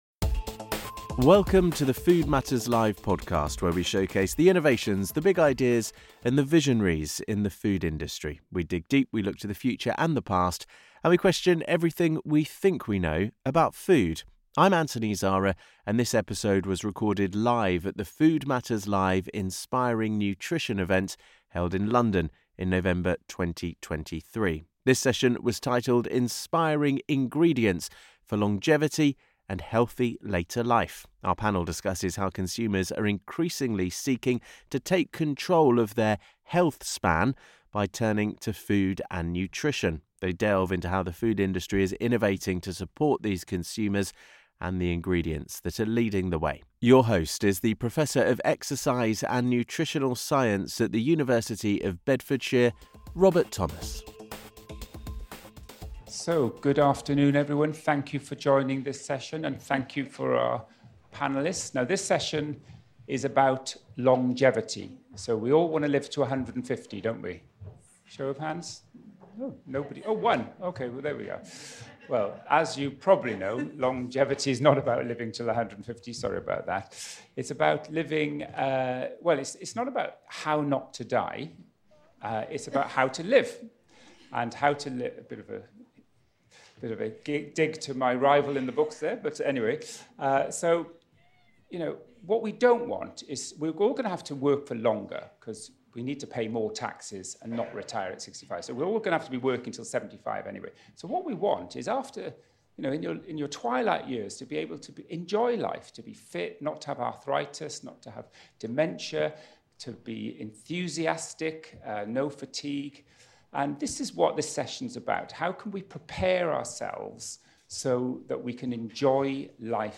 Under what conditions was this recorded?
Attend our Tastes of Better events in Manchester and Dublin In this episode of the Food Matters Live podcast, recorded at our Inspiring Nutrition event in London on November 2023, we look at how consumers are increasingly seeking to take control by turning to food and nutrition.